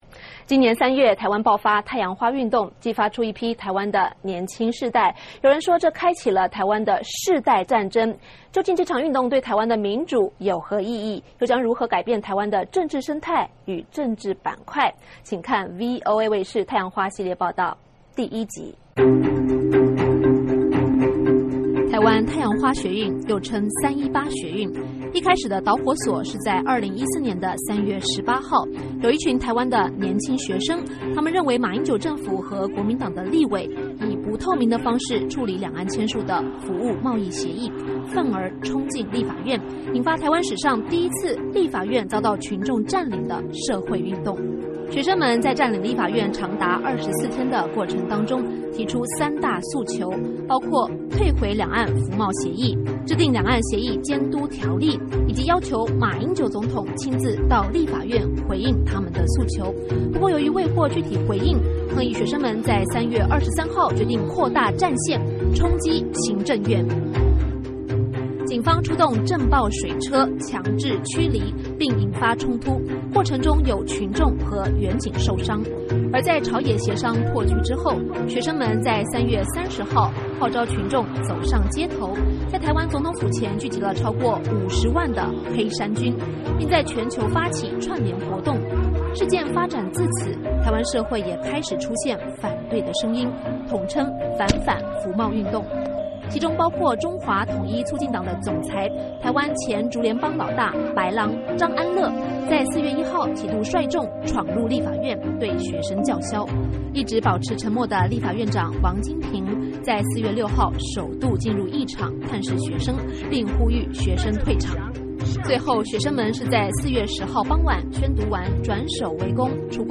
请收看VOA卫视针对台湾年轻世代以及两岸关系制作的特别专题报道太阳花三部曲：(1)台湾的世代战争(2)我爱你,台湾! (3)台港二重奏 滔滔两岸潮